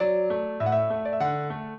piano
minuet2-6.wav